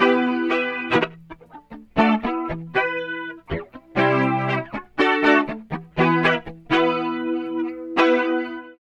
78 GTR 5  -R.wav